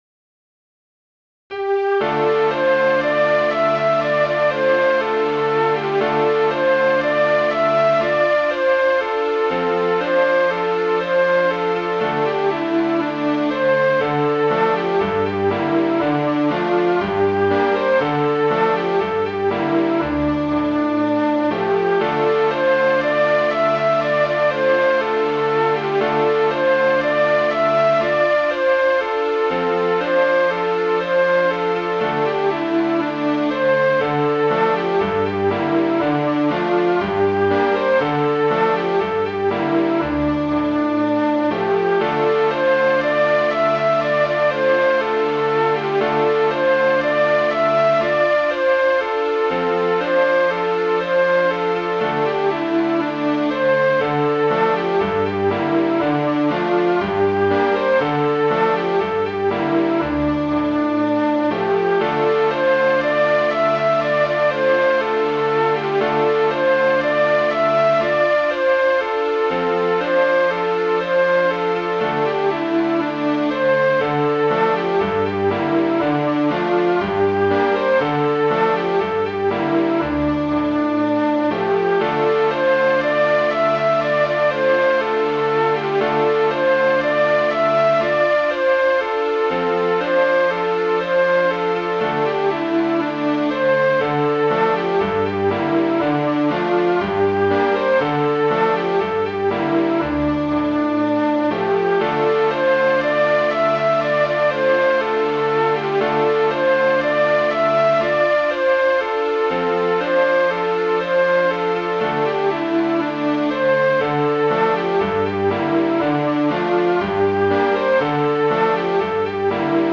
Midi File, Lyrics and Information to Edward